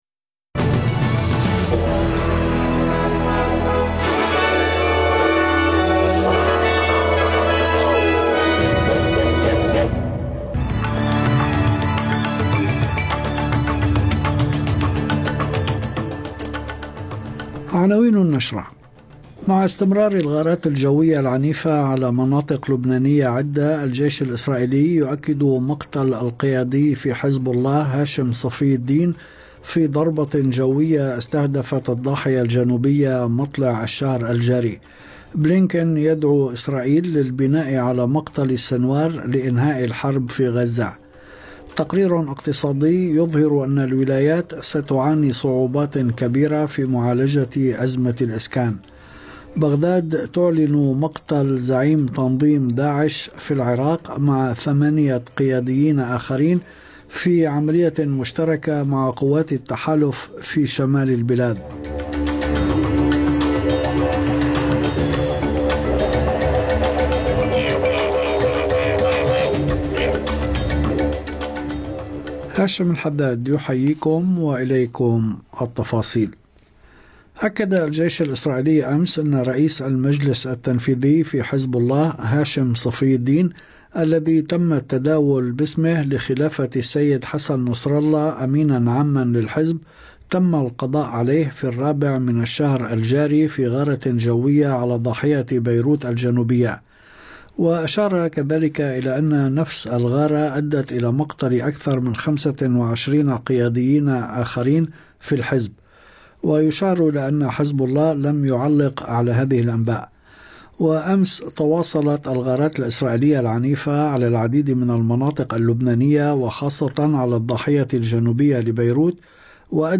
نشرة الأخبار أس بي أس عربي